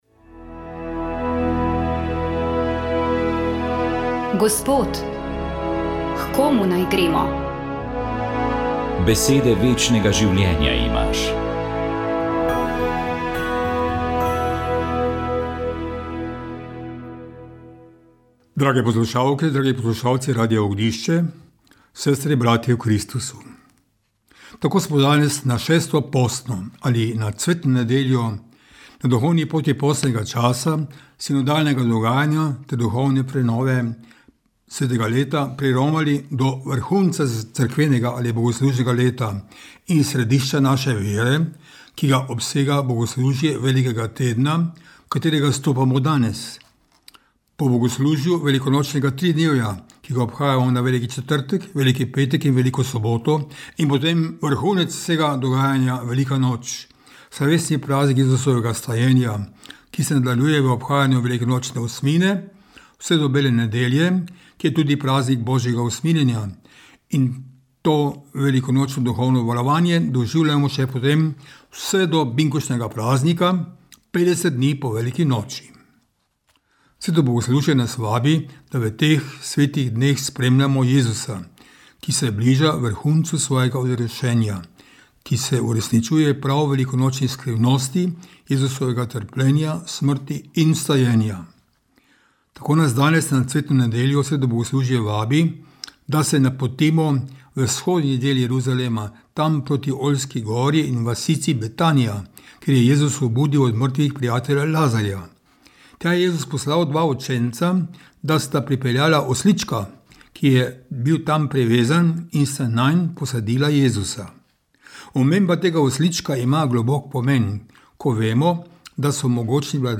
Marjan Turnšek duhovnost Duhovni nagovor VEČ ...